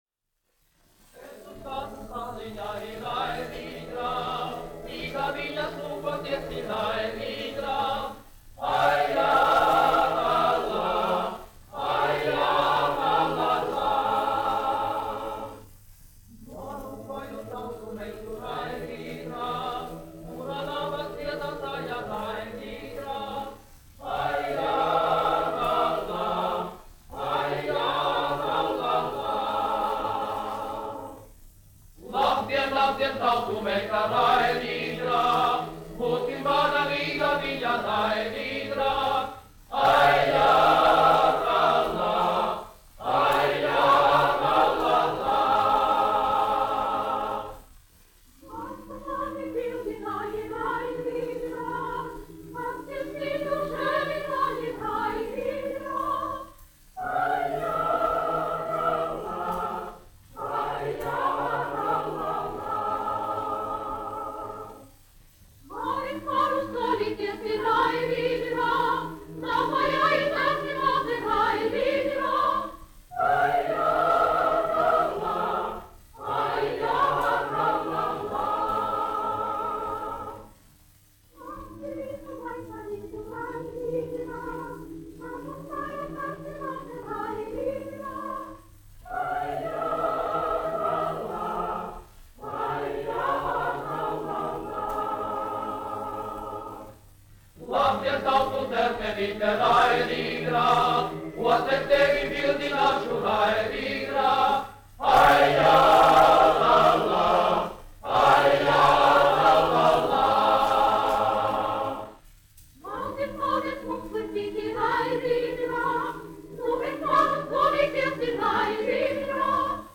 Gubene, Marija, 1872-1947, aranžētājs
Latvijas Radio Teodora Kalniņa koris, izpildītājs
Kalniņš, Teodors, 1890-1962, diriģents
1 skpl. : analogs, 78 apgr/min, mono ; 25 cm
Latviešu tautasdziesmas
Kori (jauktie)
Skaņuplate